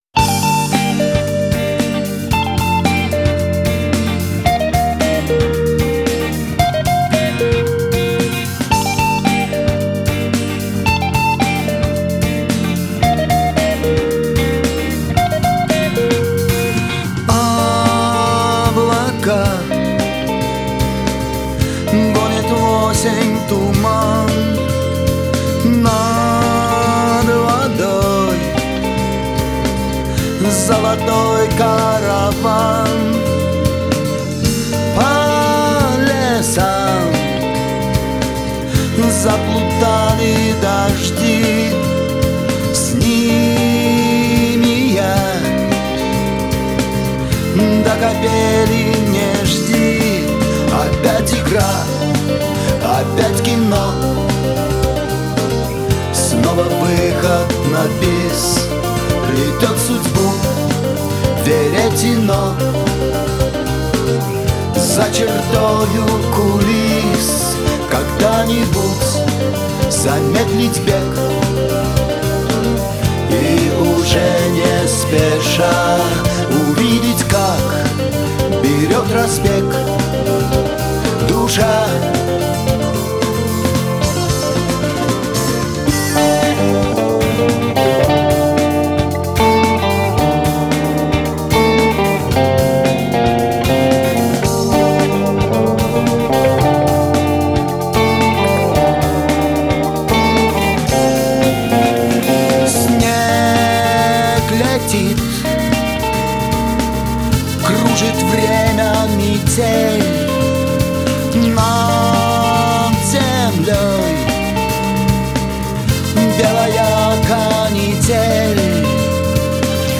рок музыка , русский рок